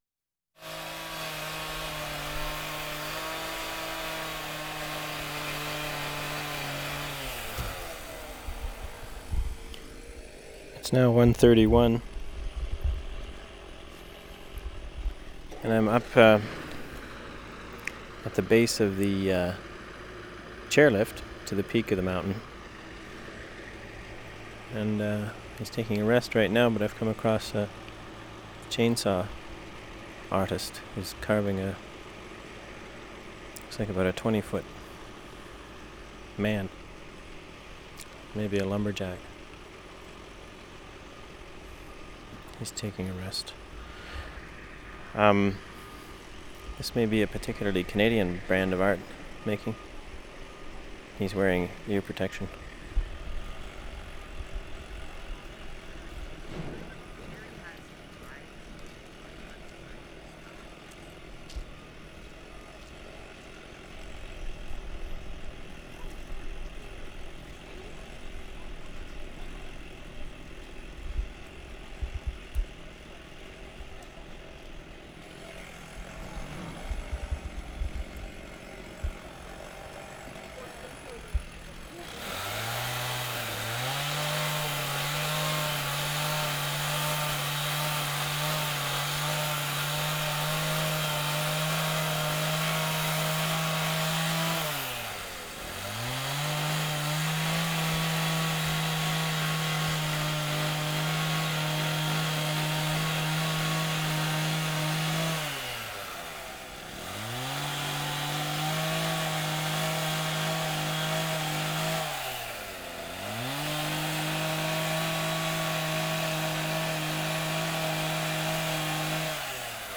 WORLD SOUNDSCAPE PROJECT TAPE LIBRARY
2. Grouse Mountain Skyride 9:51
2. doors closing, beeps, people talking, ride starts up, child sneezes at 1:38, guide starts announcement at 1:59 in an outgoing tone, hum and brake of the car over towers, signal drops out at 8:18 for a second, 9:19 PA announcement, exiting car